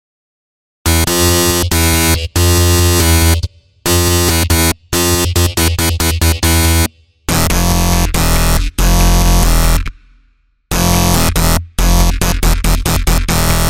Tag: 140 bpm Electronic Loops Synth Loops 2.31 MB wav Key : E FL Studio